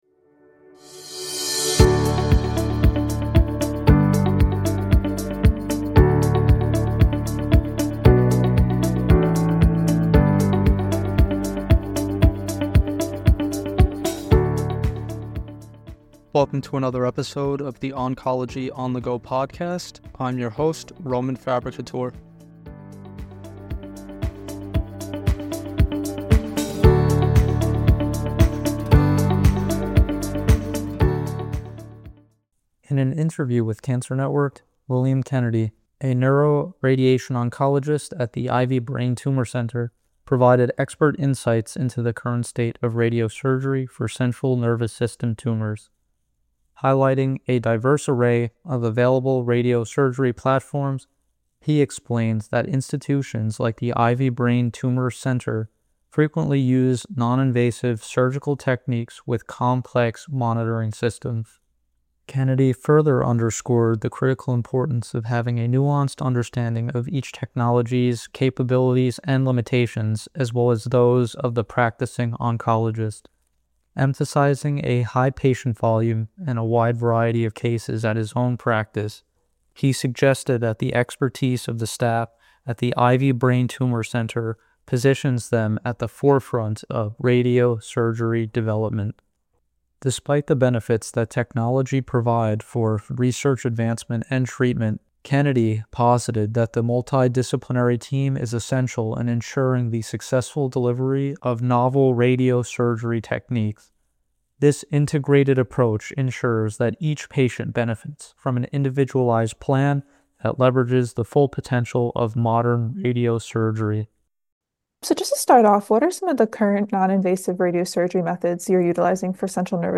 In an interview with CancerNetwork®